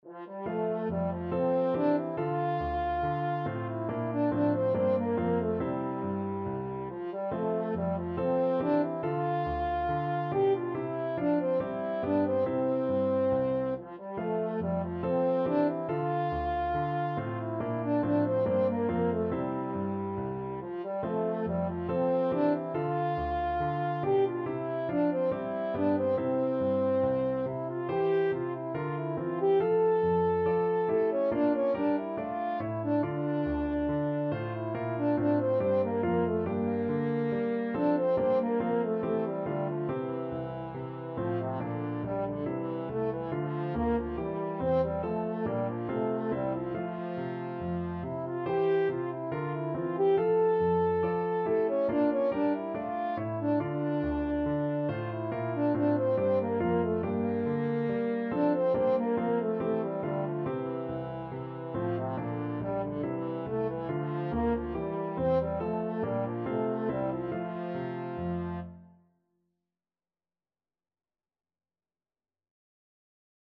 French Horn
4/4 (View more 4/4 Music)
F major (Sounding Pitch) C major (French Horn in F) (View more F major Music for French Horn )
Classical (View more Classical French Horn Music)
handel_gavotte_hwv491_HN.mp3